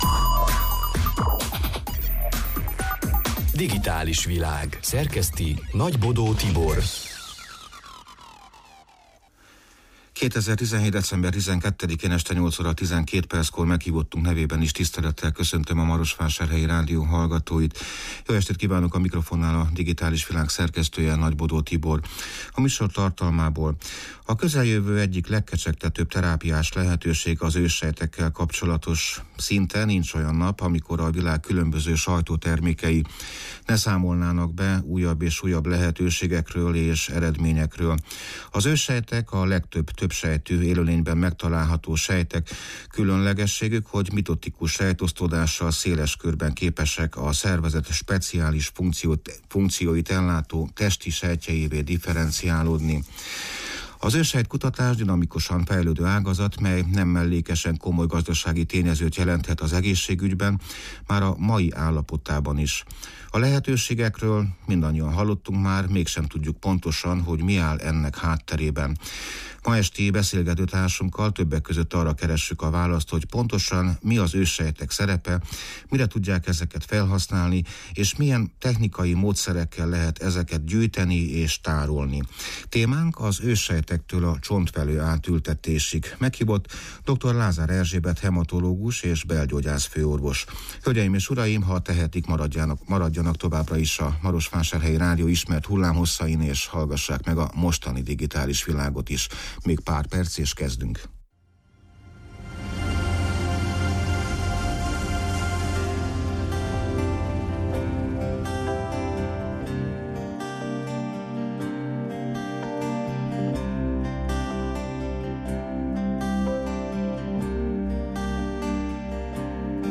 Ma esti beszélgetőtársunkkal többek között arra keressük a választ, hogy pontosan mi az őssejtek szerepe, mire tudják ezeket felhasználni, és milyen módszerekkel lehet ezeket gyűjteni és tárolni.